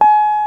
JAZZGUITAR 2.wav